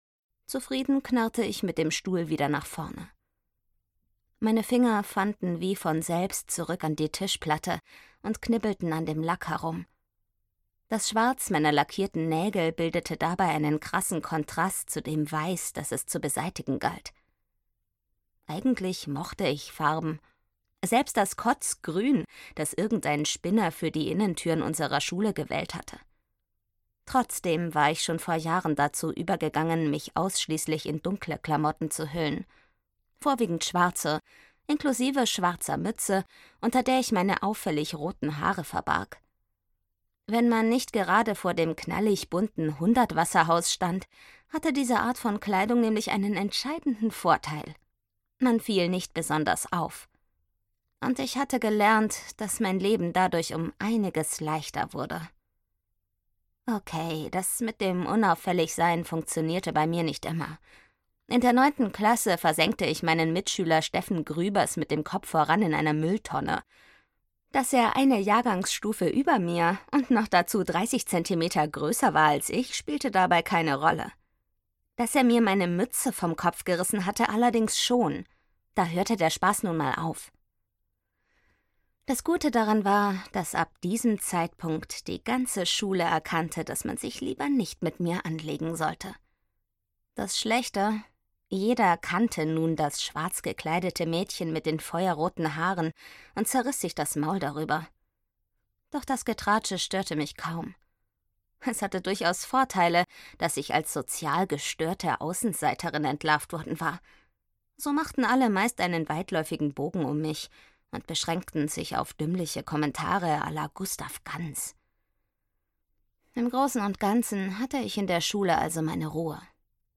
Secret Elements 1: Im Dunkel der See - Johanna Danninger - Hörbuch